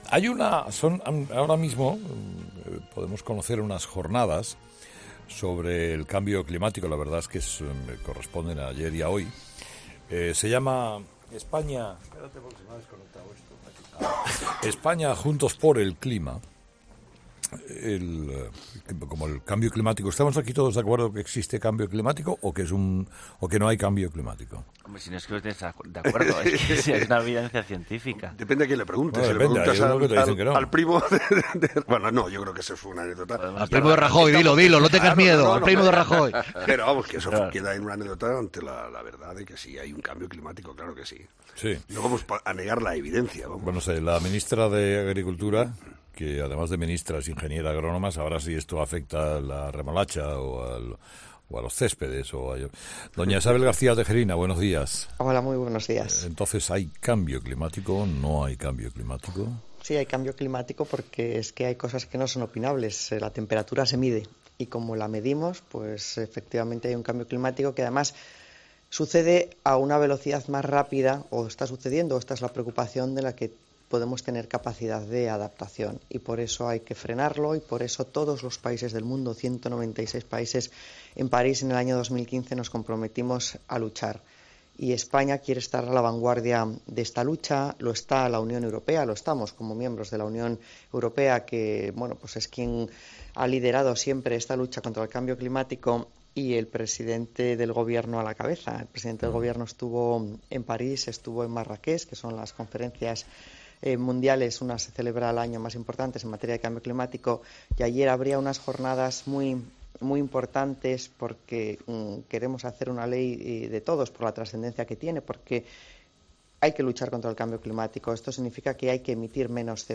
Escucha la entrevista a la ministra de Medio Ambiente, Isabel García Tejerina, en 'Herrera en COPE'